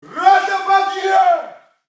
Звук крика солдата Рота подъем